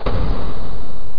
DOORCLOS.mp3